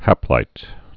(hăplīt)